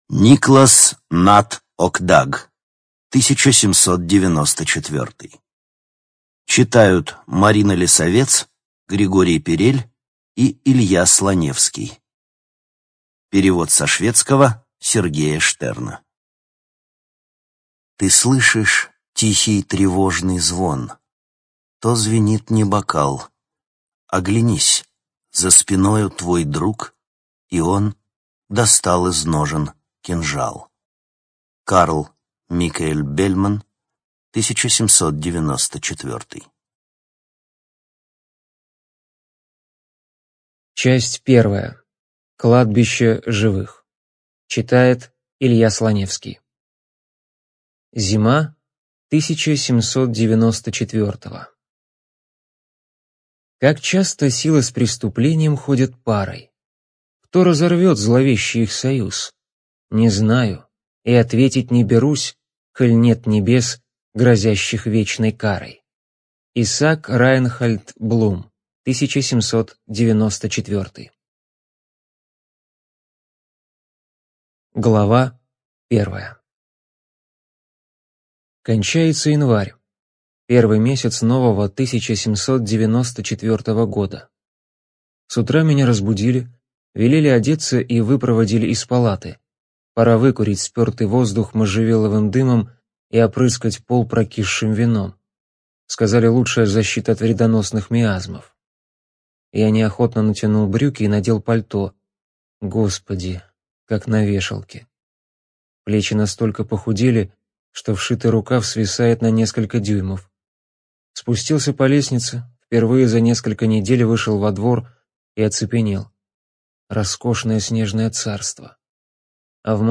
Студия звукозаписиStorytel